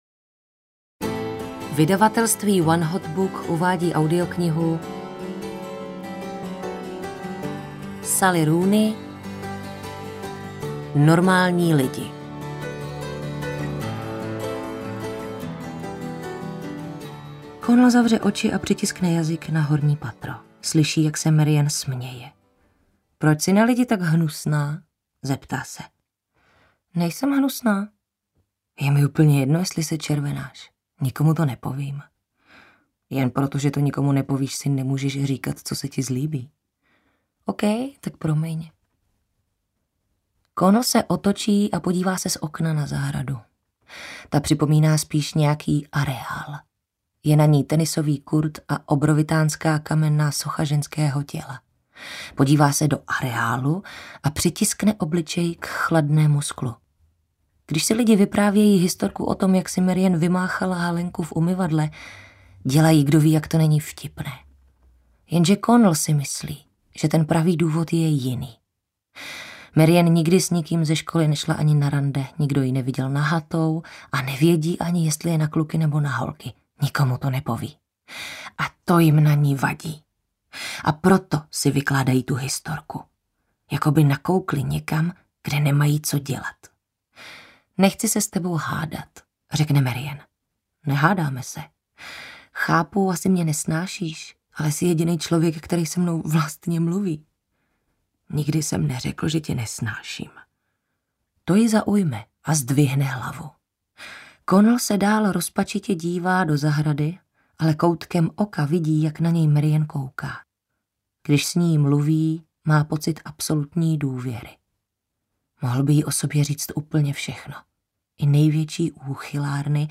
Normální lidi audiokniha
Ukázka z knihy
• InterpretTereza Dočkalová